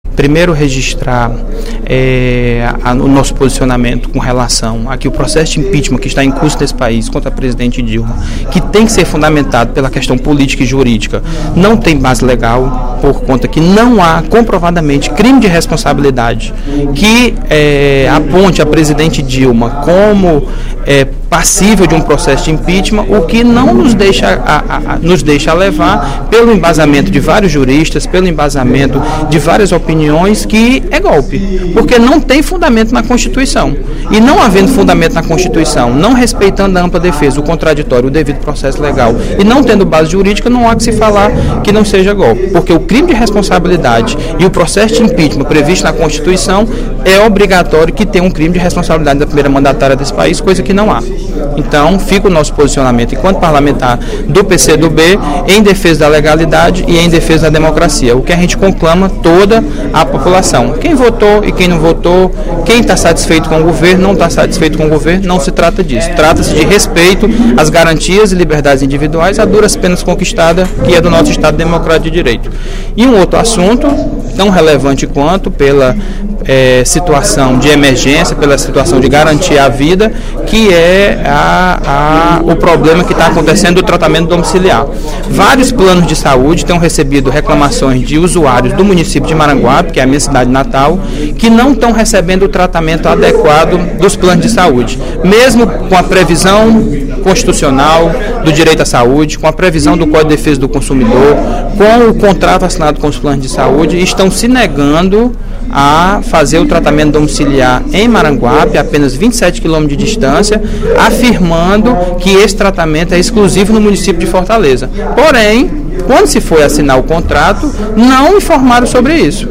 O deputado George Valentim (PCdoB) destacou, em pronunciamento no primeiro expediente da sessão desta sexta-feira (01/04) as manifestações que ocorreram em todo o País, em apoio à presidente Dilma Rousseff e contra o impeachment.